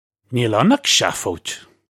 Pronunciation for how to say
Nyeel unn uch sha-foadge
This is an approximate phonetic pronunciation of the phrase.